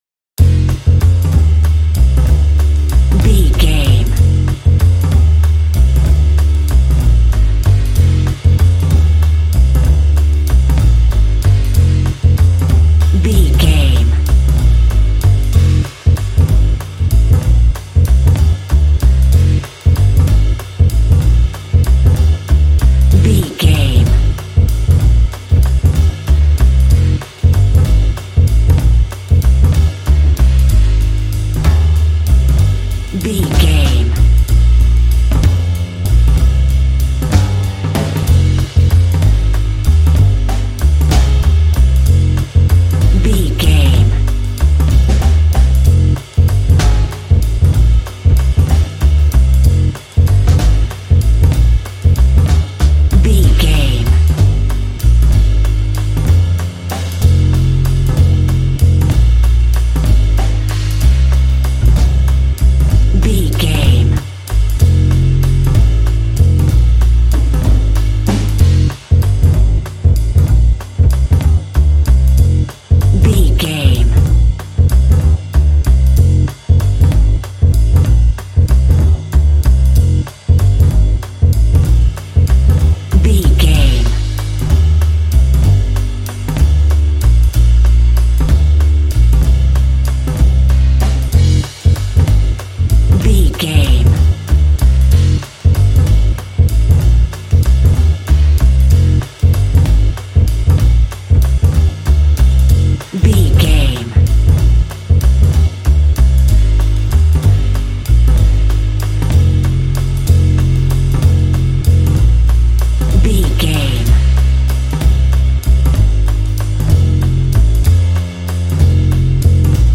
Smooth jazz track reminiscent of classic jazz standards.
Aeolian/Minor
melancholy
smooth
double bass
drums
jazz
swing